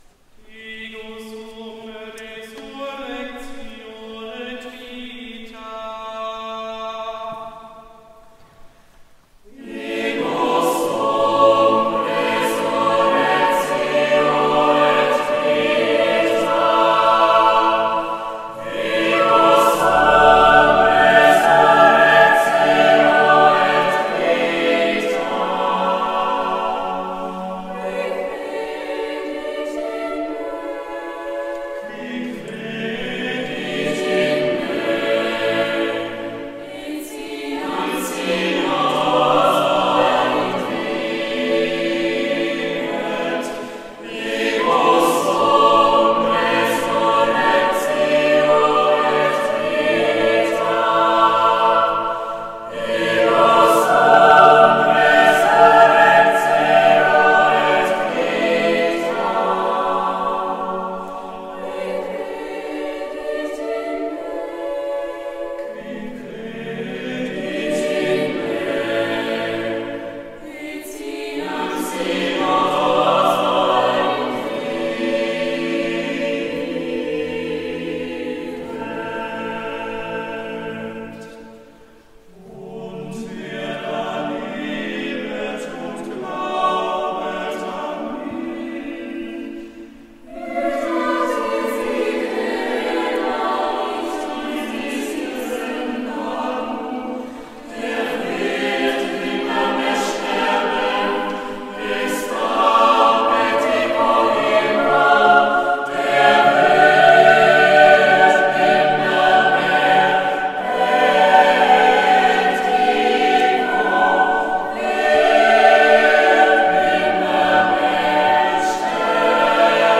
Voicing: "SATB divisi"